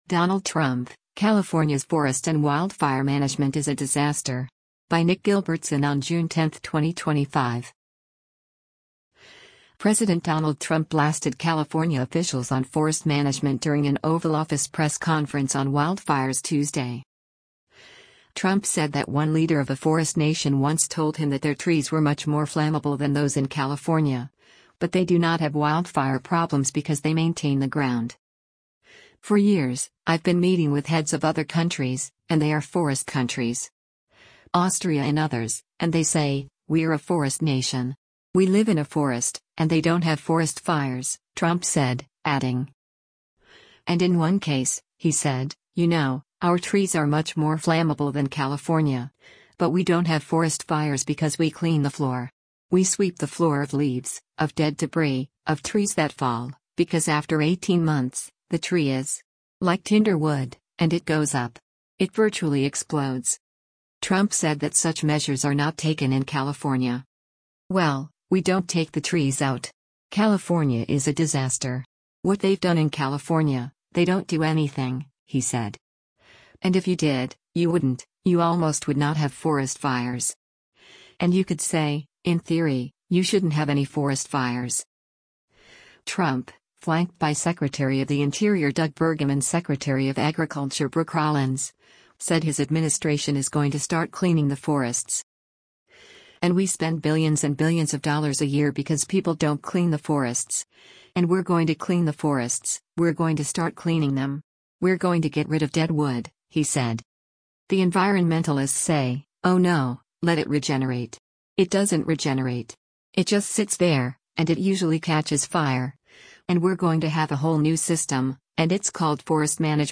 President Donald Trump blasted California officials on forest management during an Oval Office press conference on wildfires Tuesday.